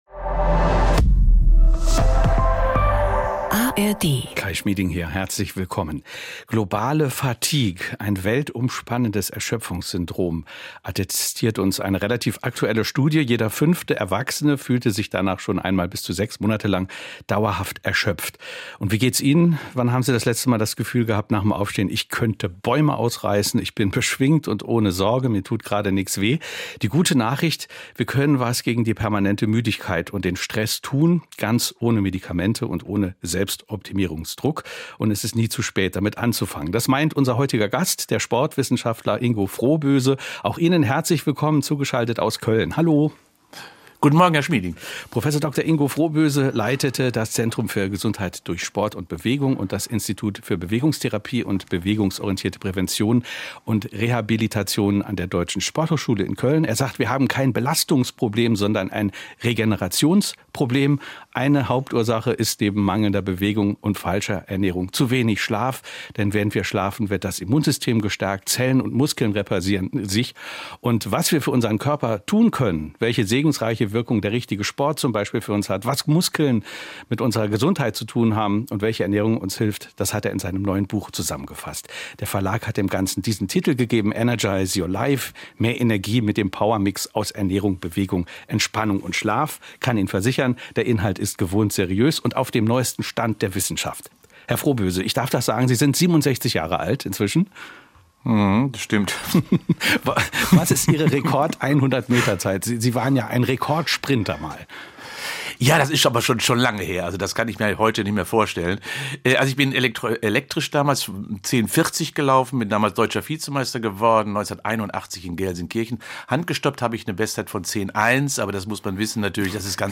Die traditionsreichste Sachbuchsendung im deutschen Sprachraum stellt seit über 50 Jahren jeweils ein Buch eines Autors eine Stunde lang im Gespräch vor. Die Themen reichen von Politik und Wirtschaft bis zu Gesundheit, Erziehung oder Psychologie.